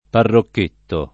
parrocchetto [ parrokk % tto ] s. m.